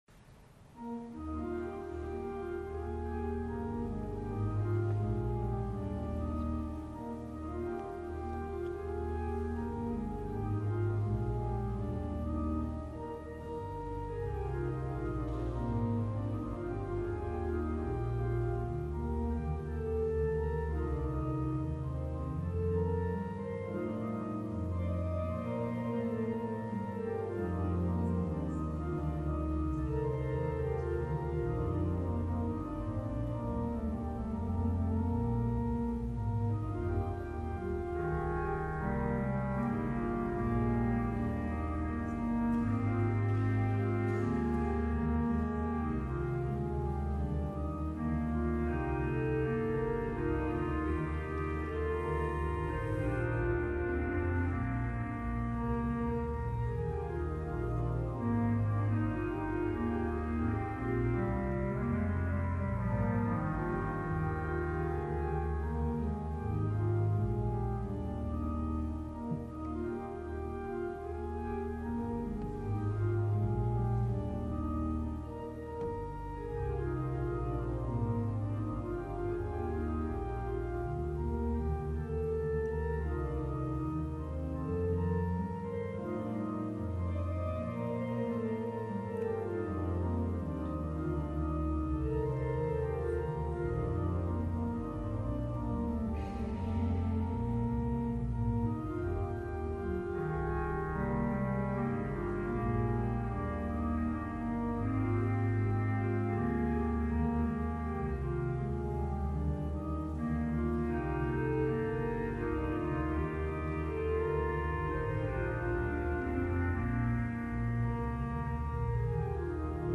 orgue Saints-Anges-Gardiens, Lachine, Québec.